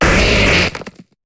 Cri de Grolem dans Pokémon HOME.